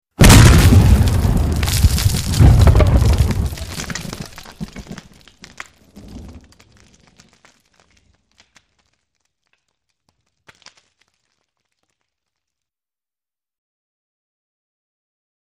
Grenade- Defensive with Debris